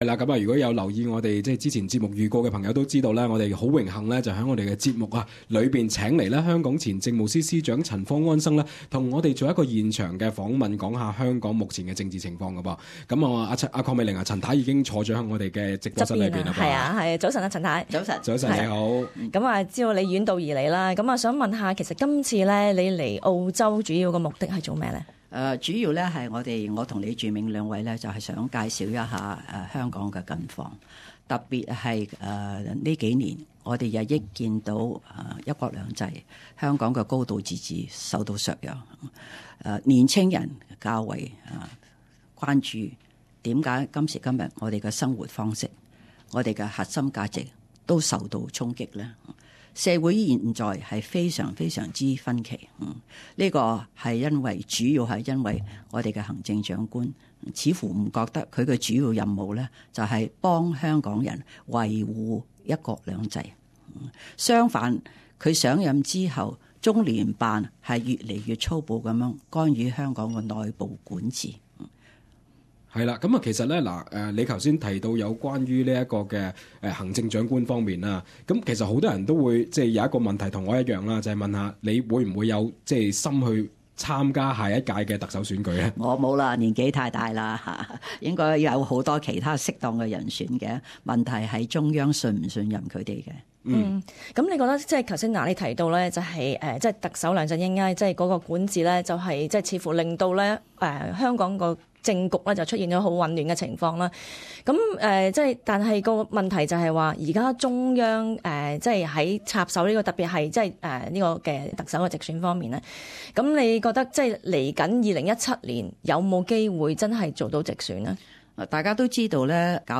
【時事專訪】 訪問陳方安生（附短片）